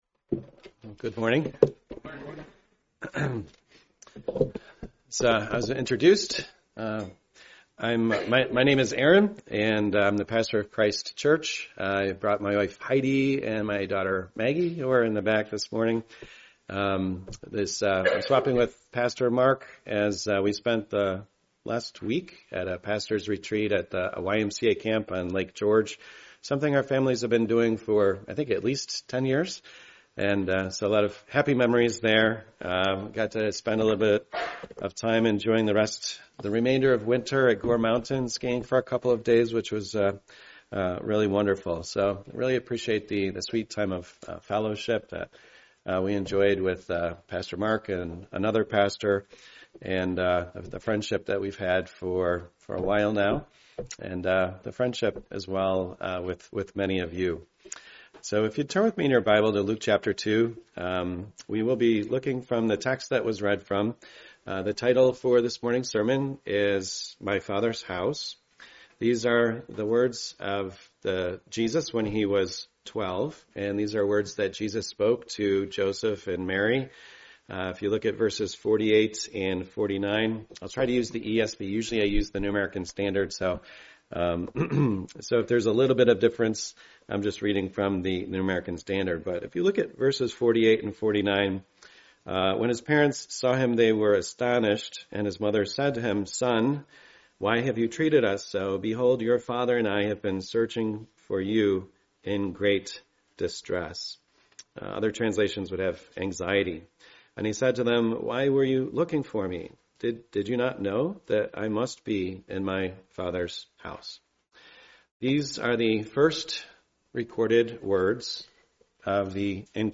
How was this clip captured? Part of the Guest Speaker series, preached at a Morning Service service.